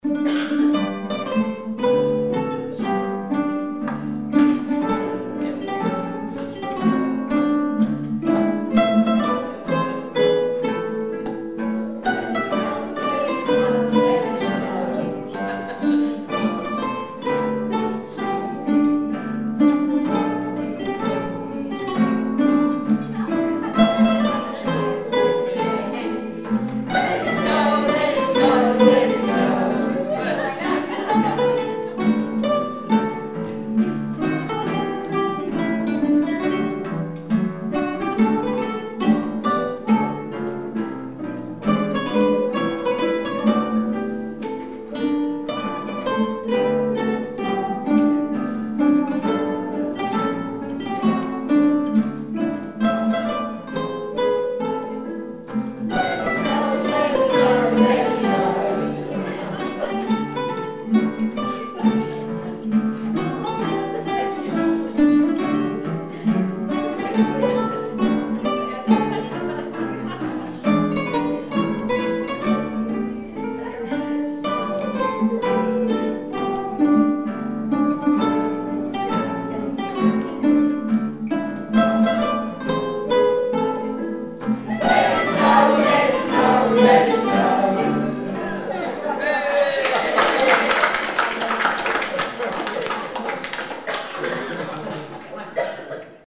So what happens when the audience only knows 3 words of a Christmas piece?
This does - recorded at our Carol Concert last night at a Sheltered Housing Complex in central Portsmouth.
We couldn't get the microphone positioned well so it's mainly top and bottom and no middle!